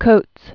(kōts)